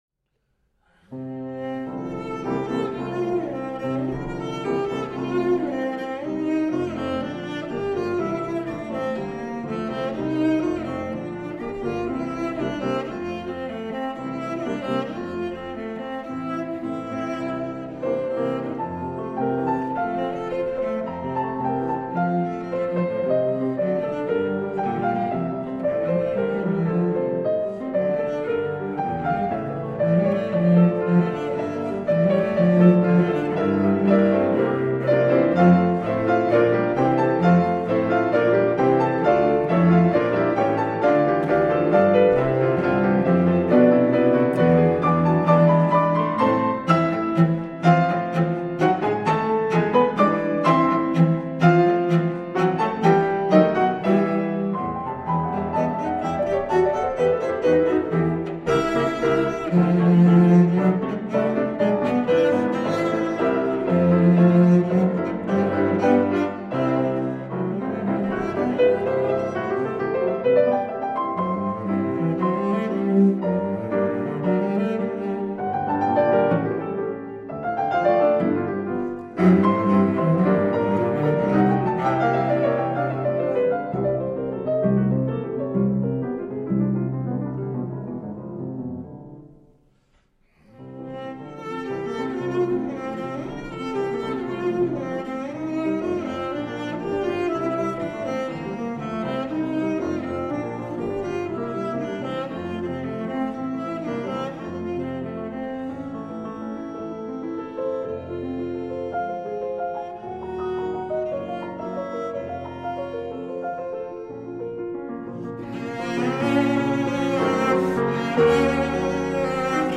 violoncello